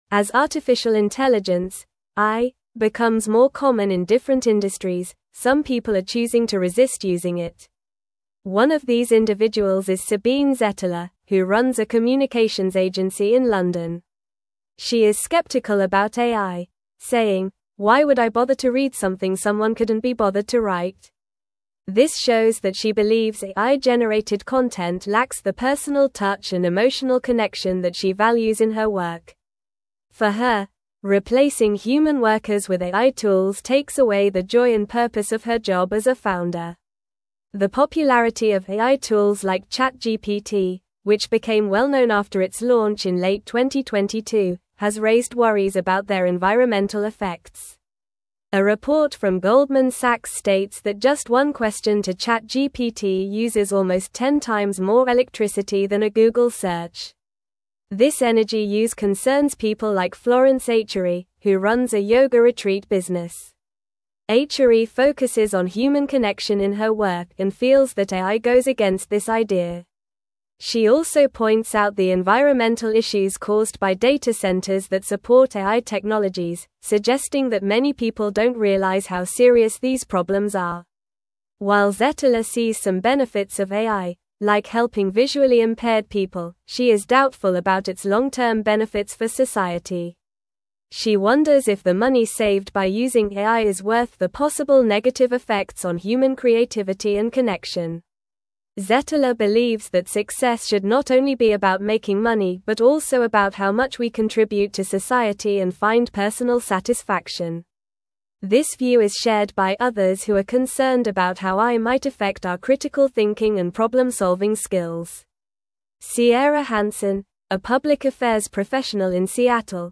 Normal
English-Newsroom-Upper-Intermediate-NORMAL-Reading-Resistance-and-Acceptance-of-AI-in-Creative-Industries.mp3